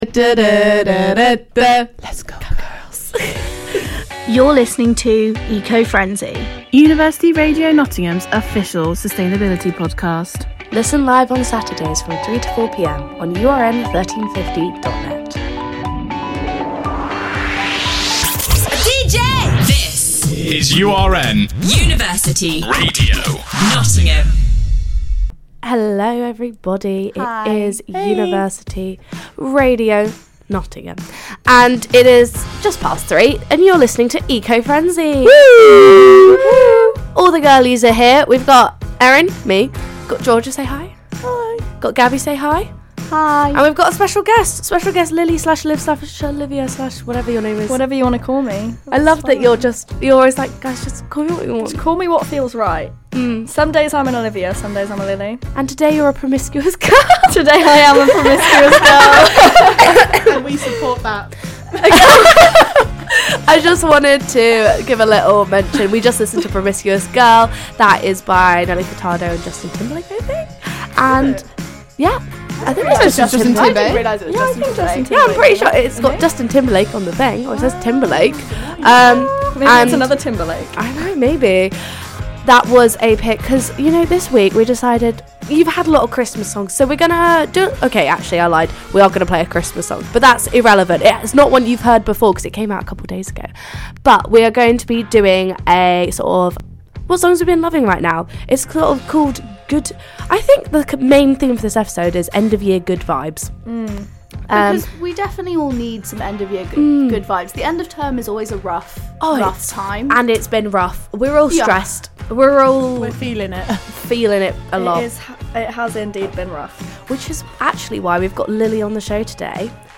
~ Originally broadcast live on University Radio Nottingham on Saturday 10th December 2022